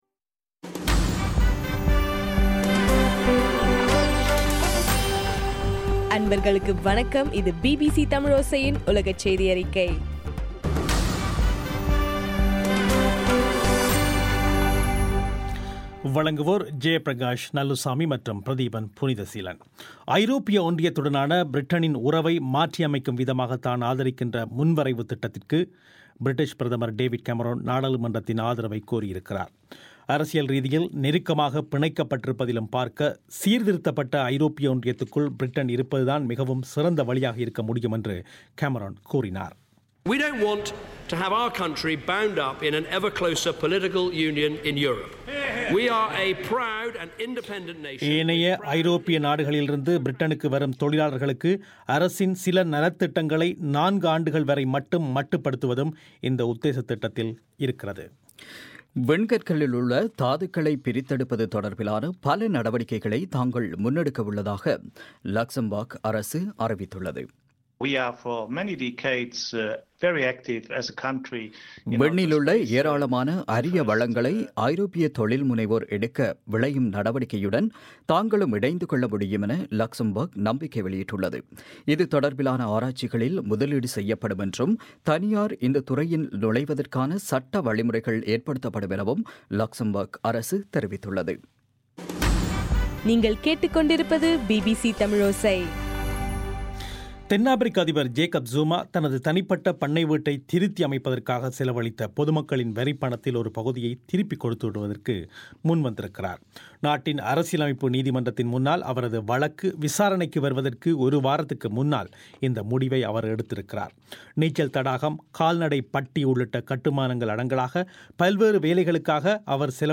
பிப்ரவரி 3, 2016 பிபிசி தமிழோசையின் உலகச் செய்திகள்